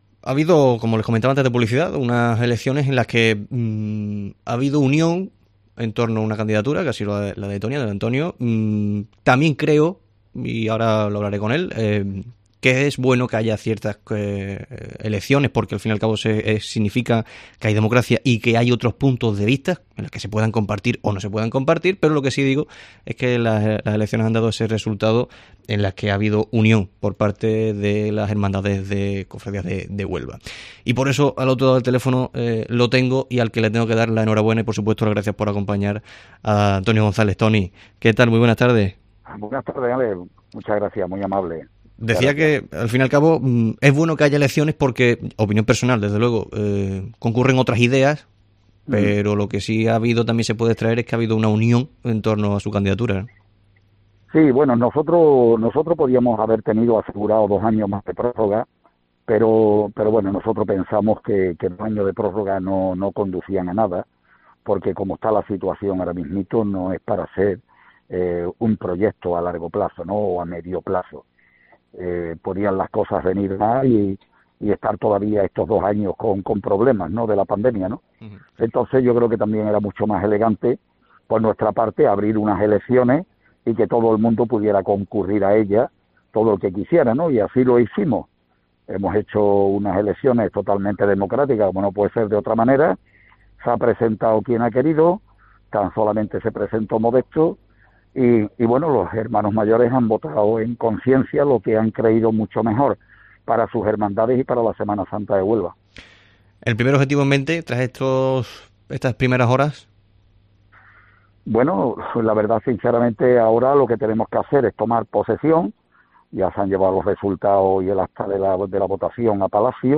Hoy ha tenido un hueco para estar en el Herrera en COPE Huelva donde se ha mostrado agradecido por esta unión en cuanto a su proyecto continuista cuyo objetivo está en "asegurar que la Semana Santa de 2022 pueda celebrarse con total seguridad y con plenas garantías."